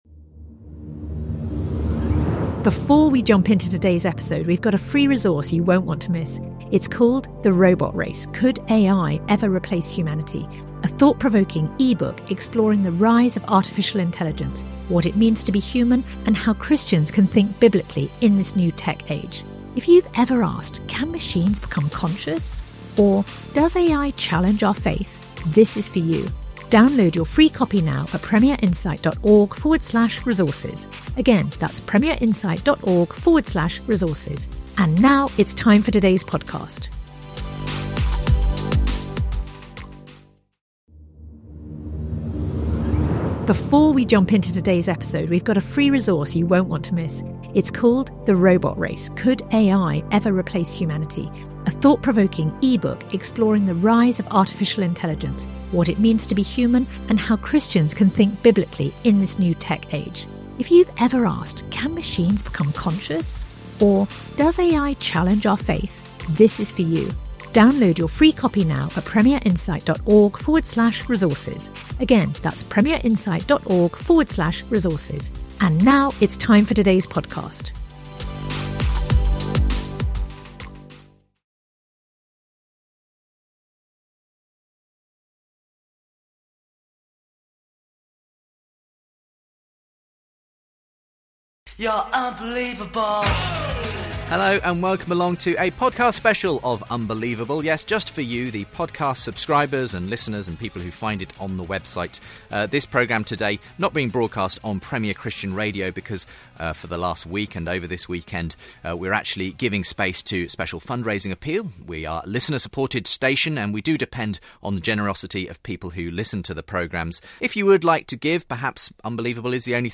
Unbelievable? John Lennox Premier Lecture - Going Against the Flow
Christianity, Religion & Spirituality 4.6 • 2.3K Ratings 🗓 3 July 2015 ⏱ 71 minutes 🔗 Recording | iTunes | RSS 🧾 Download transcript Summary Mathematician and Christian thinker John Lennox gave the Premier Lecture for the 20th Anniversary of the Radio station on June 10th. Drawing on his new book ‘Against the Flow’, Lennox draws lessons from the book of Daniel for the church today.